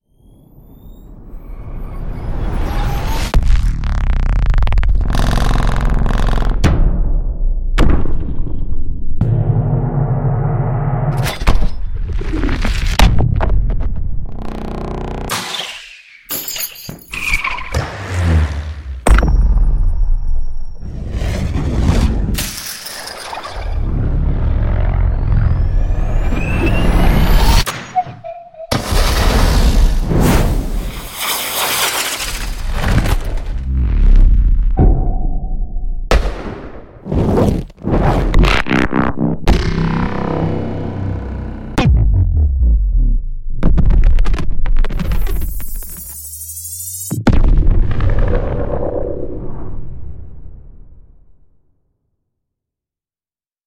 音效素材-燃放烟花能量冲击发射飞行无损声效2300组
该资源包含强劲的冲击音效、深厚的低频过渡、逼真的烟火爆炸声以及其他多种类型的音频材料，质感细腻、动态层次分明，非常适合各类创意音效设计项目。
所有实录内容均以 384kHz 高采样率捕捉，并使用可录制超高频的专业麦克风，保留了丰富的细节和超声波成分，便于大幅度调整音高和进行深度的声音变形处理。